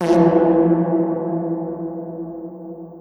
mvm_sentrybuster_explode.wav